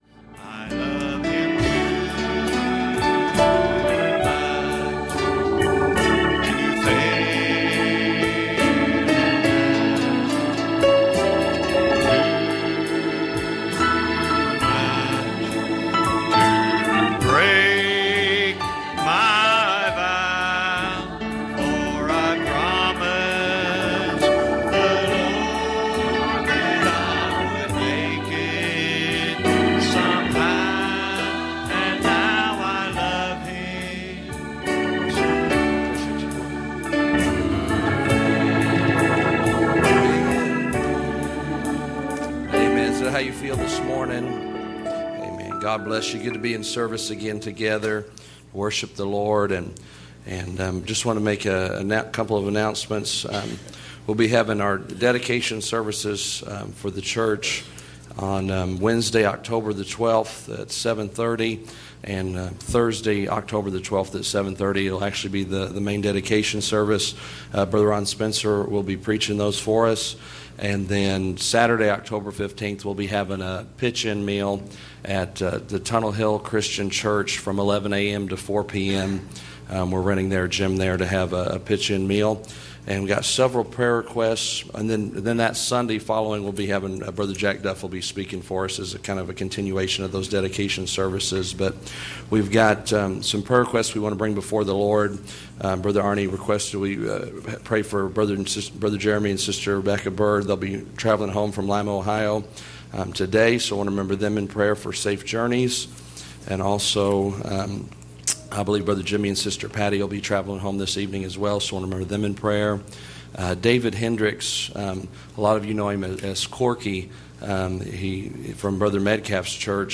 Passage: Luke 2:7 Service Type: Sunday Morning